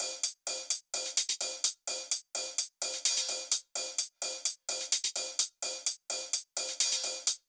VFH2 128BPM Tron Quarter Kit 7.wav